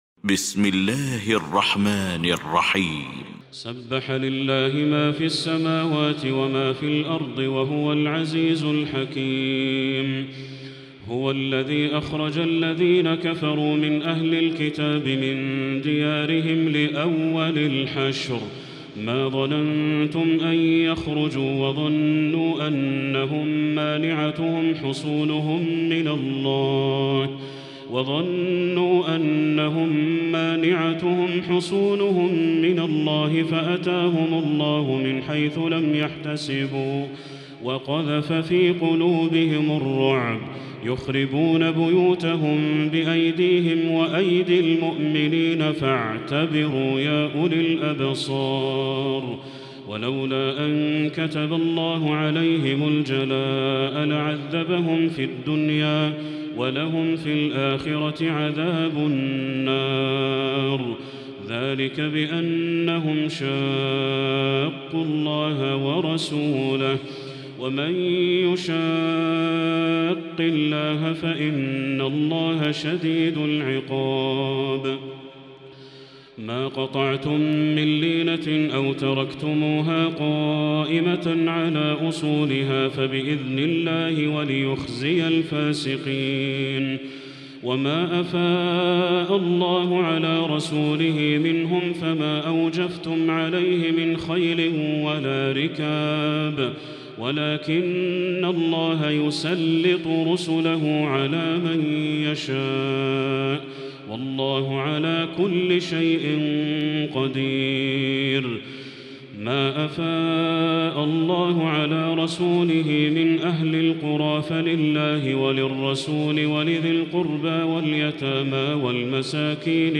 المكان: المسجد الحرام الشيخ: بدر التركي بدر التركي الحشر